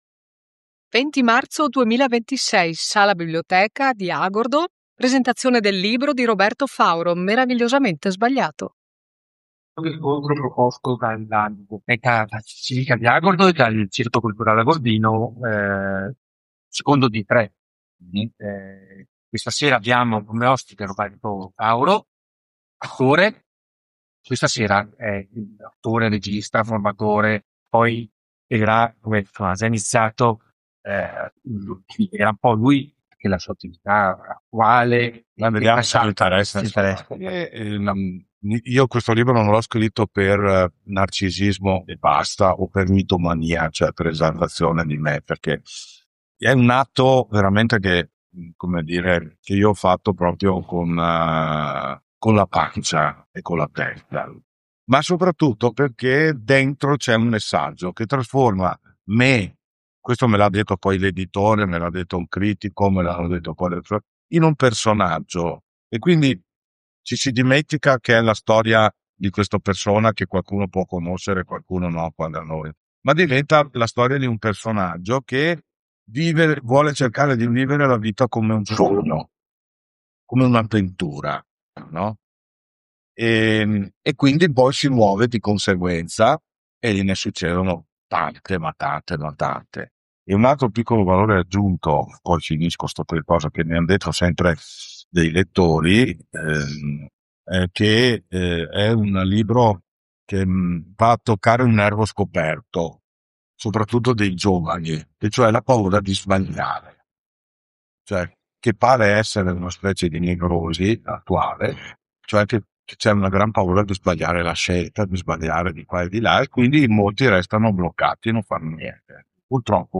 AUDIO MIGLIORATO